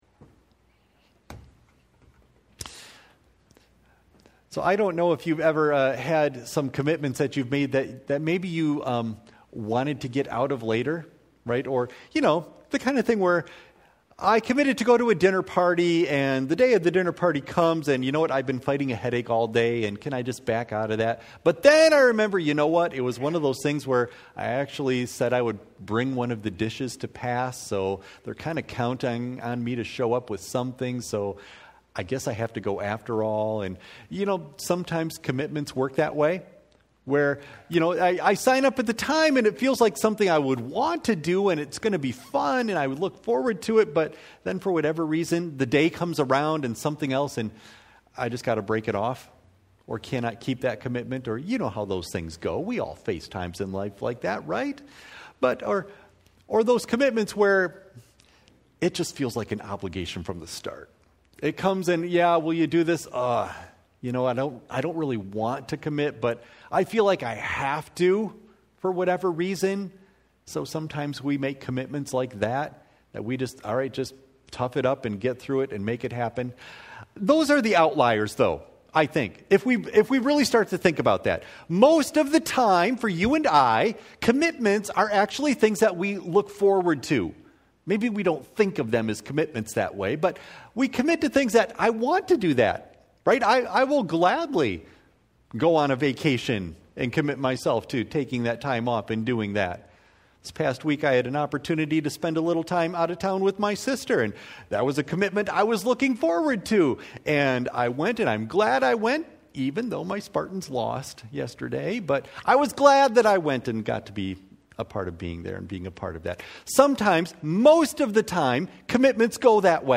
Audio of Message